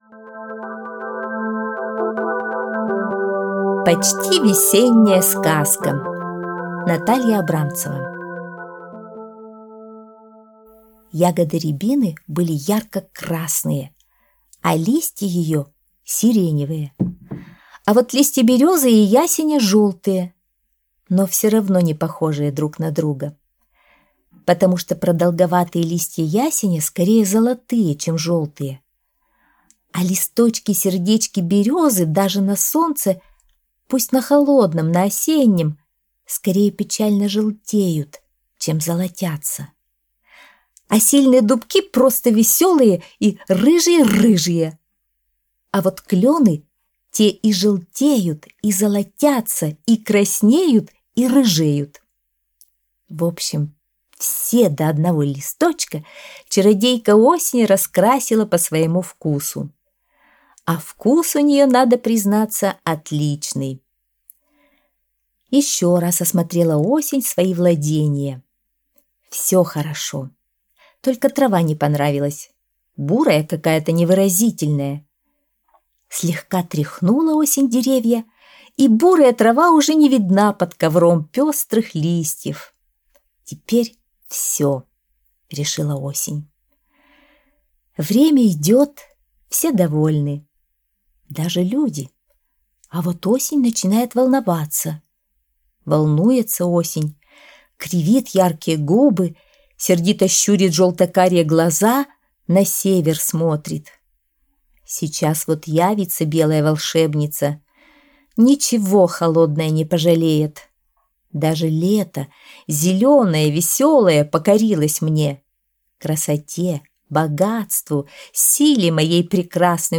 Почти весенняя сказка - аудиосказка Натальи Абрамцевой - слушать онлайн | Мишкины книжки